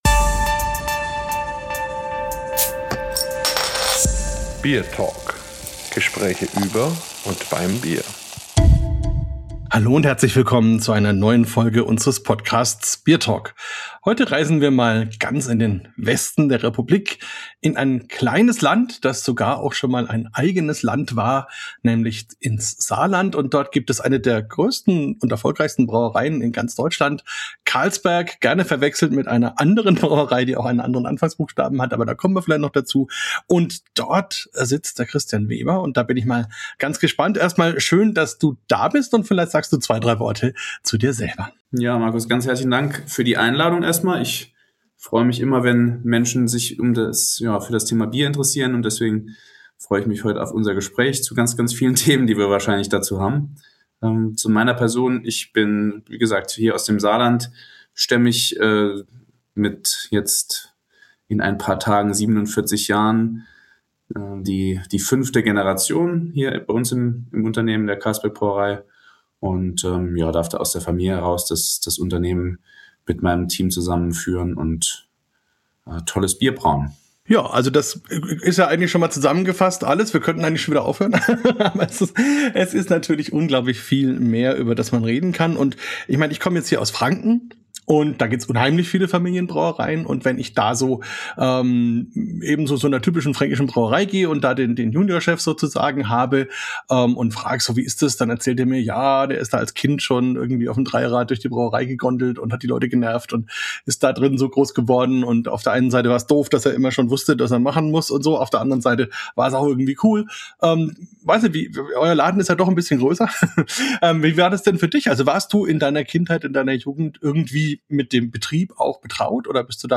BierTalk 159 – Interview